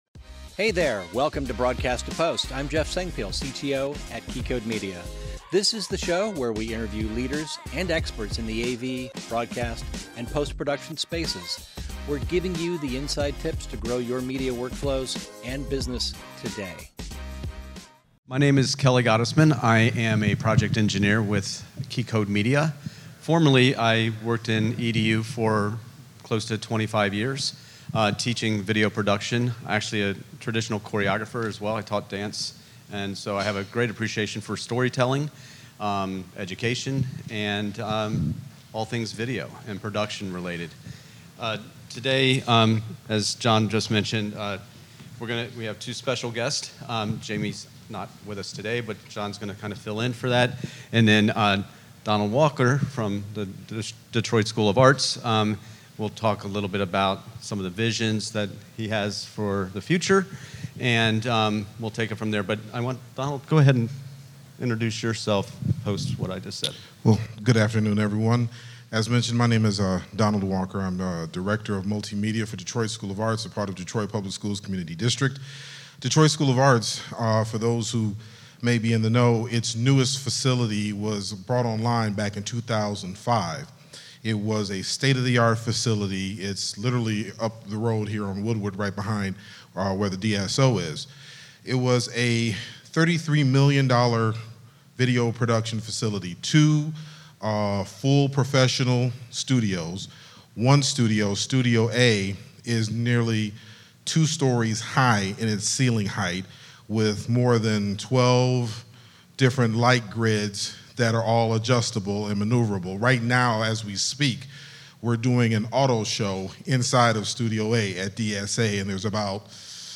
This is a live recording from our PostNAB Detroit event.